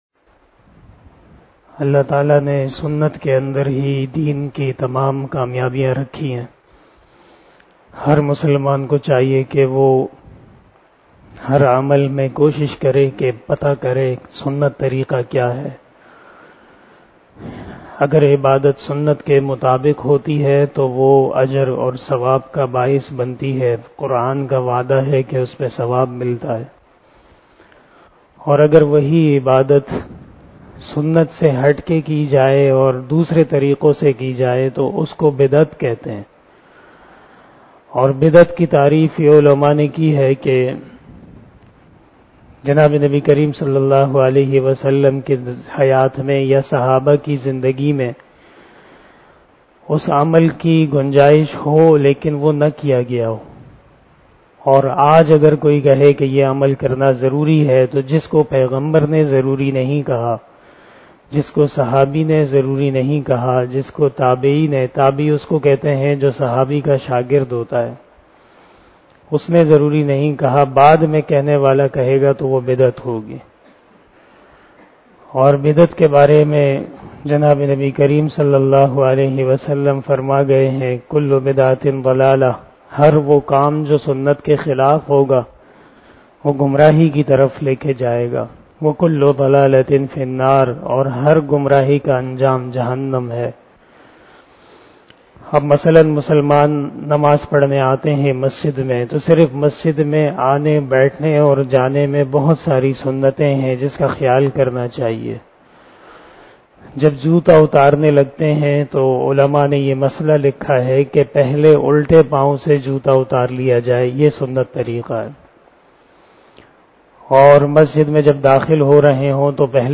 057 After Asar Namaz Bayan 25 April 2022 ( 23 Ramadan 1443HJ) Monday